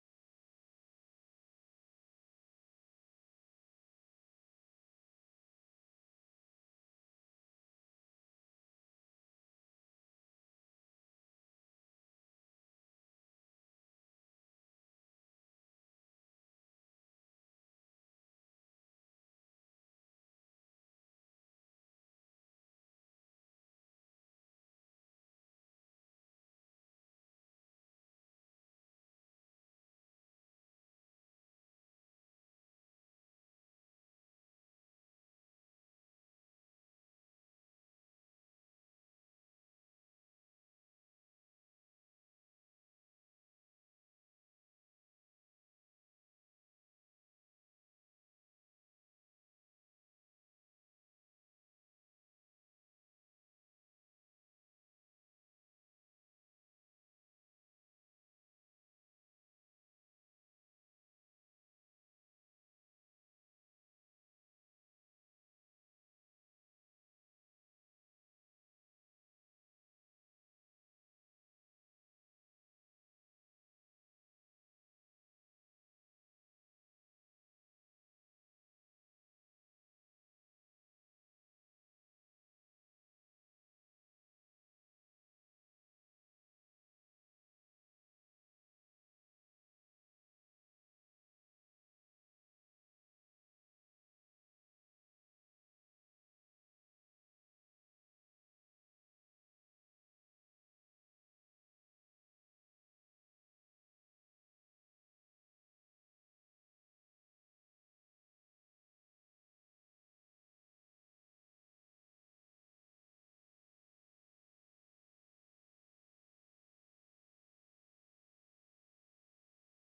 سورة الكهف - المحاضرة 6